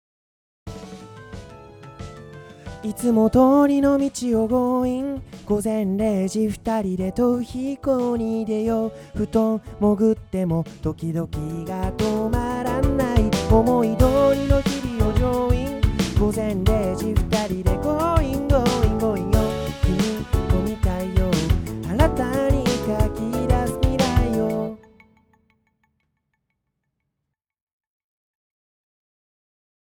KSM104と大枠は似ているのだが、上で書いた持続的な音が軽減され中域の反応が良くなっている。
なので、声以外の楽器で中域が埋まってしまっている編成だと、この独特な中域の音色が活かせないのでチョイスからは外れる。